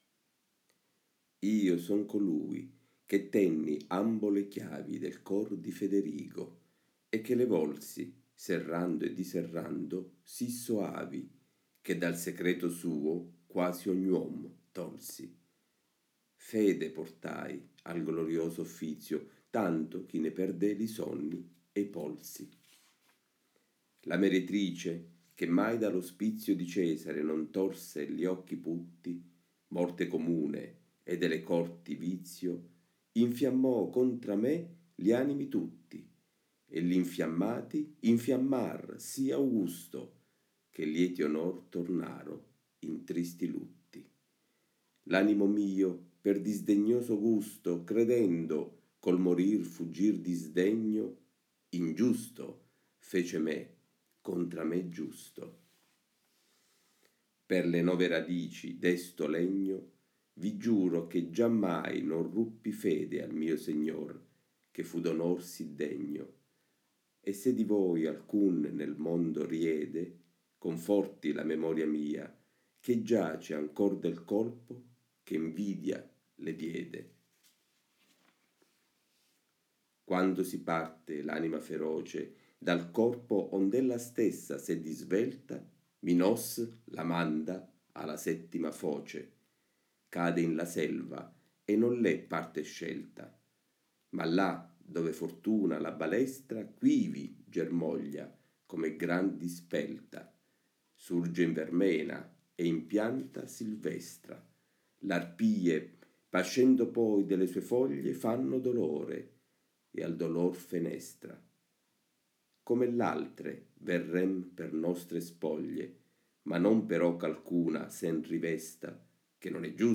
AUDIOLETTURE DANTESCHE, 1: INFERNO – IL POTERE, L’INVIDIA E IL SUICIDIO